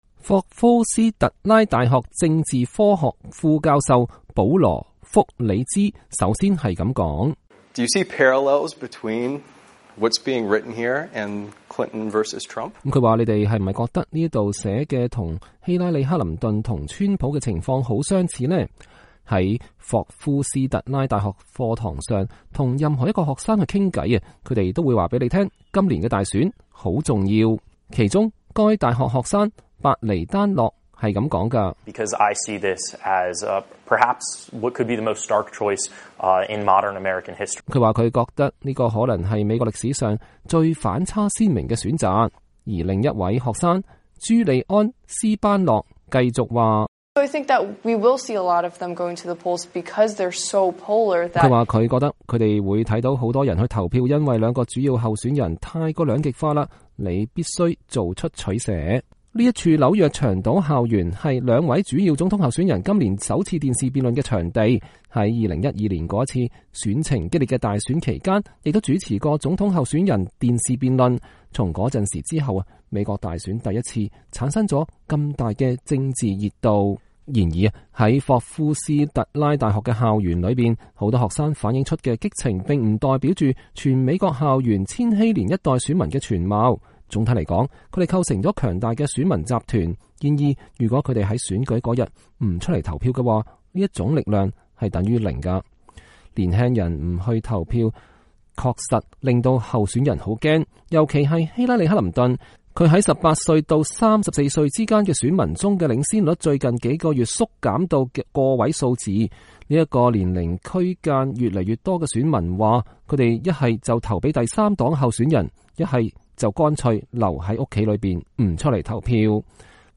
纽约州亨普斯特德 —
這里的學生向美國之音講述了他們的擔憂。